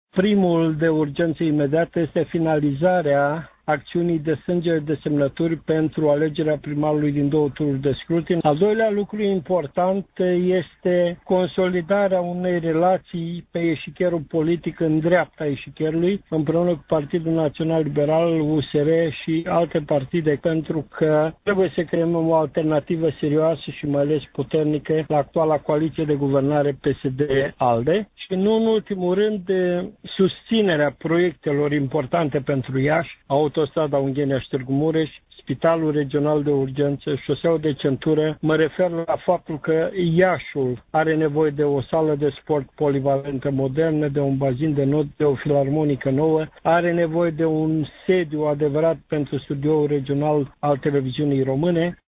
Deputatul Petru Movilă a declarat, pentru Radio Iași, că principalele proiecte ale organizației PMP sunt finalizarea acțiunii de strângere de semnături pentru alegerea primarului din două tururi de scrutin și consolidarea dreptei politice, alături de PNL și USER, ca alternativă la Alianța PSD-ALDE.